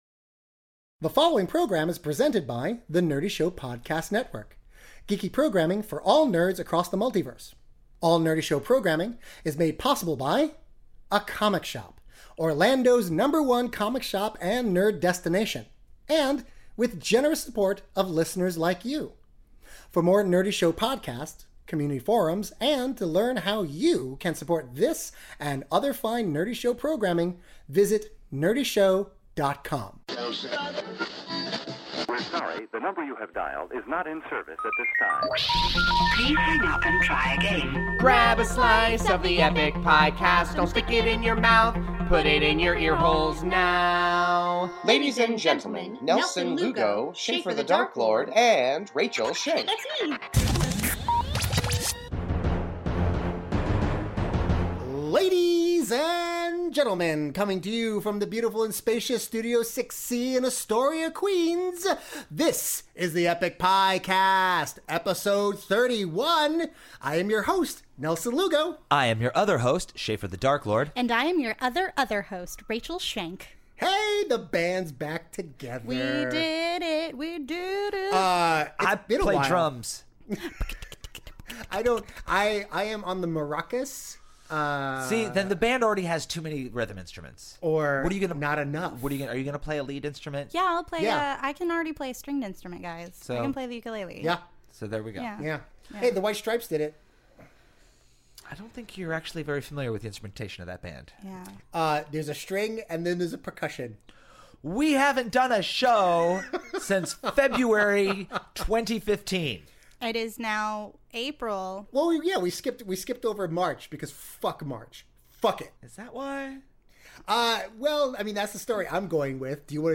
The triumvirate of snark return to Studio 6C just in time to talk about trailers, Tax Day, and the worst jobs in the world.